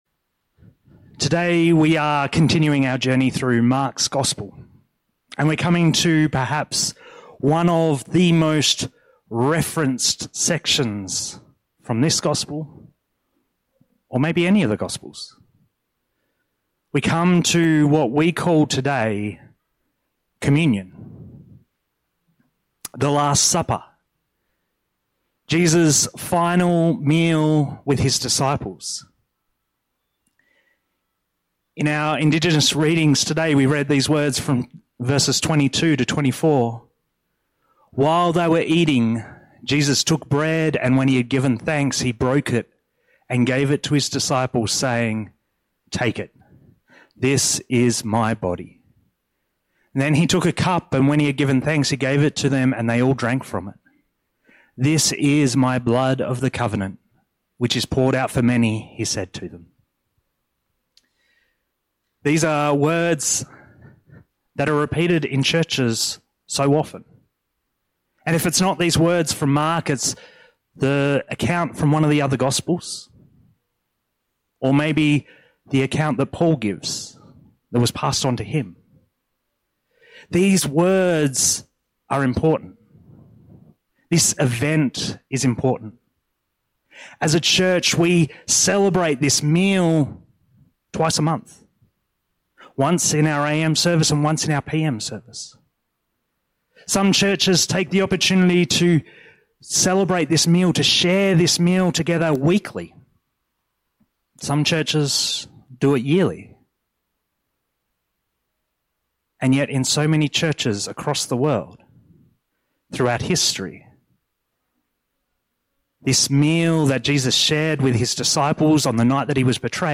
Sermons – Alice Springs Baptist Church